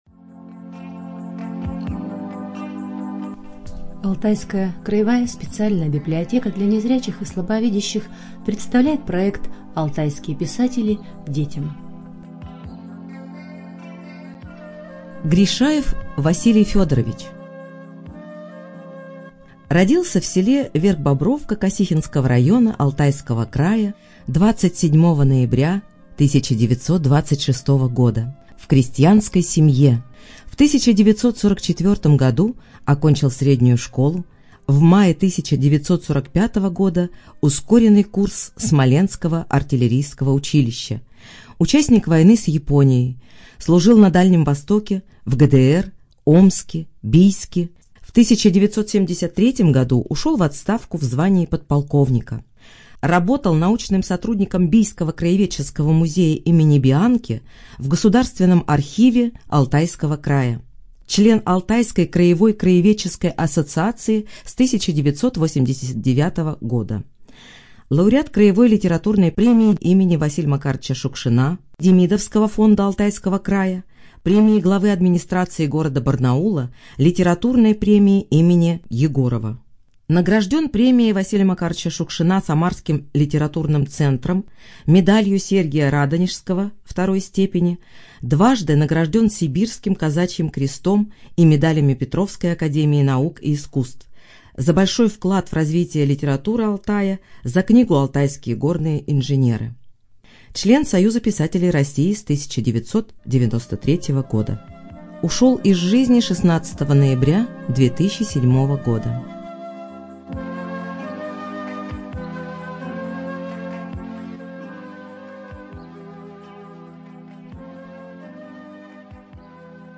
Студия звукозаписиАлтайская краевая библиотека для незрячих и слабовидящих